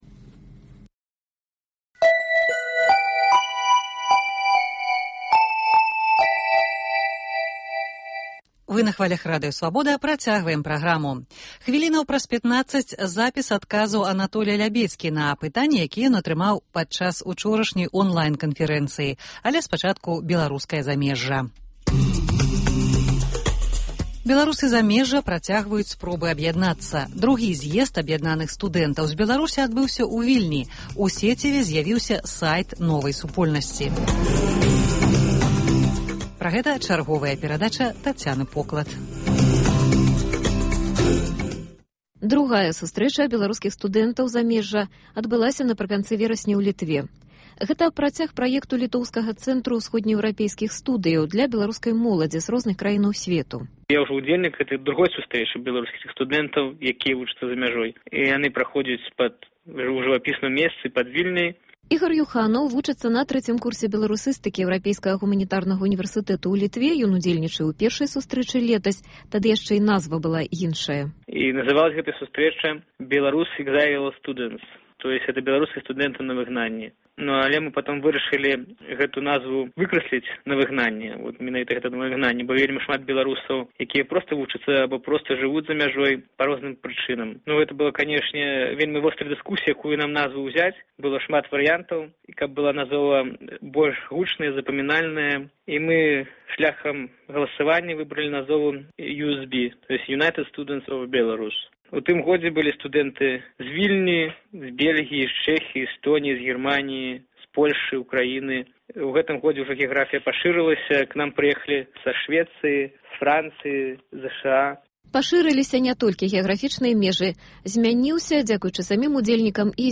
Беларуская дыяспара ў глябалізаваным сьвеце. Размова пра беларускія студэнцкія таварыствы за мяжой.